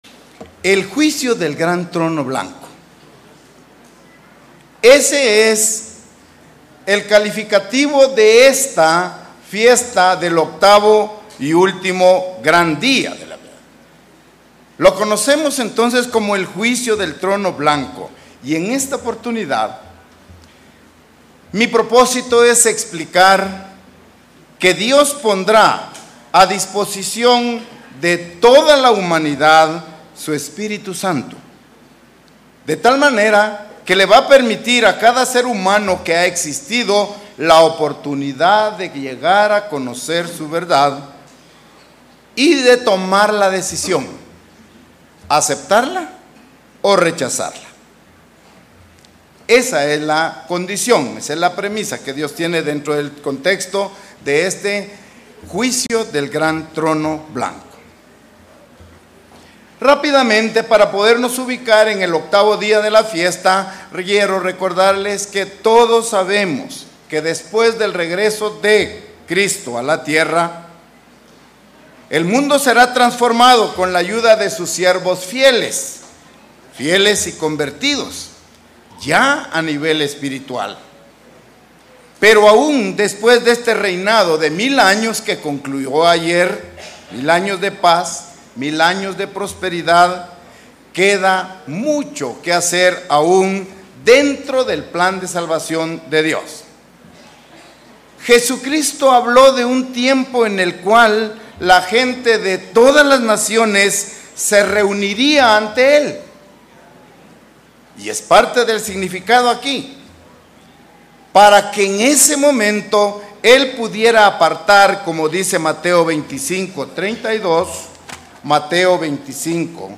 Mensaje entregado durante el UGD 2019.